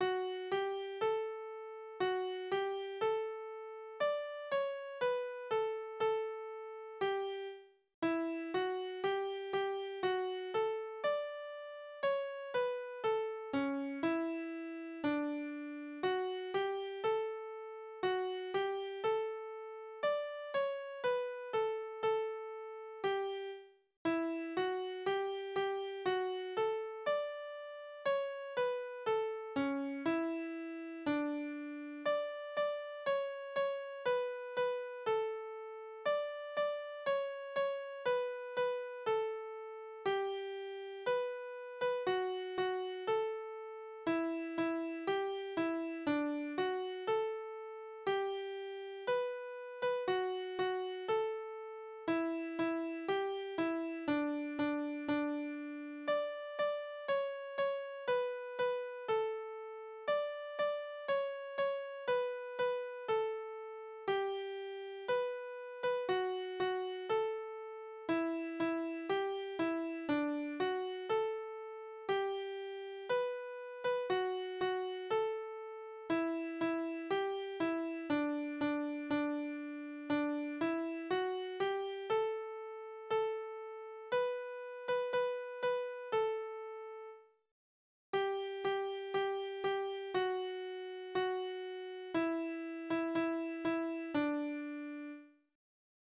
Kindertänze: Hier ist grün
Tonart: D-Dur
Taktart: 4/4
Tonumfang: kleine None